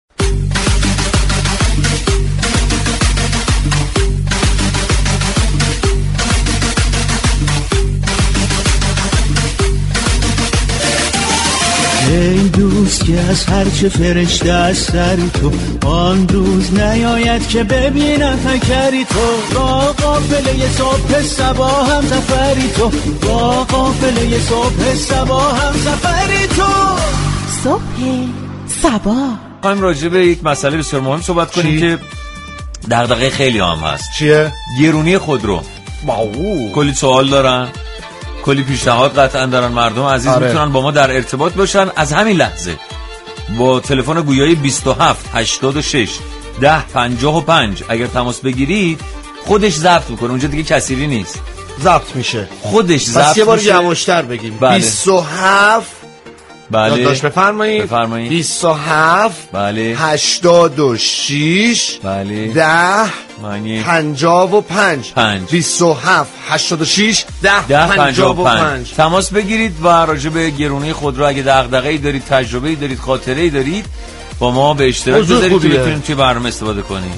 رادیو صبا در برنامه "صبح صبا " بانگاهی طنز به نقد و بررسی قیمت های خودرو داخلی می پردازد.
برنامه صبح صبا در فضایی شاد و از طریق تعامل با مخاطبان و پخش آتیم های متناسب با موضوع برنامه به نقد و بررسی طنازانه قیمت های خودروهای داخلی می پردازدو